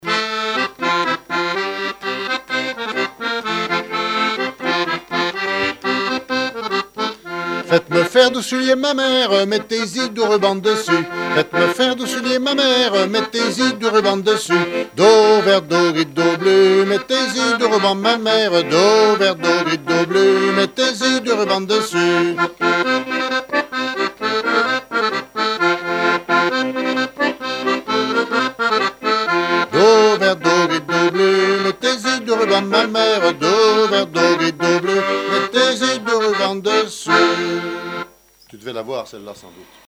Saint-Christophe-du-Ligneron
Couplets à danser
branle : courante, maraîchine
Pièce musicale inédite